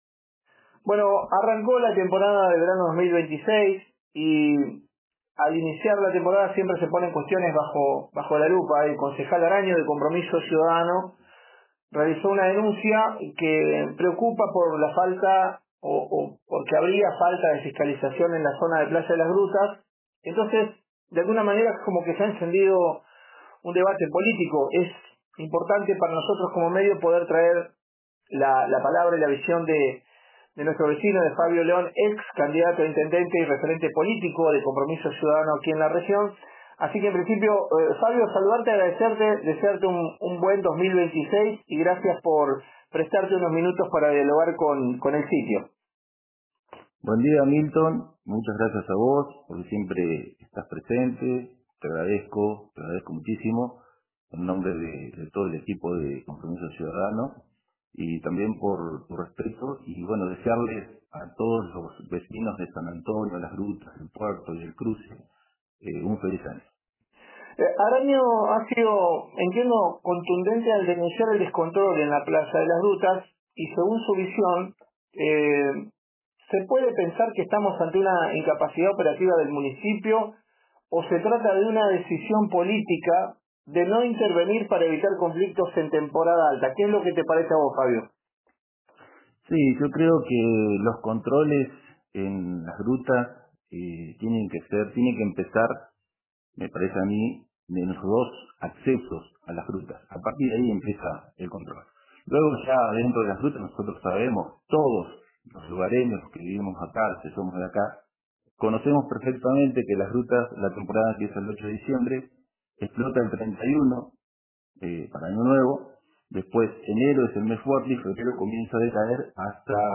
Audio de la entrevista: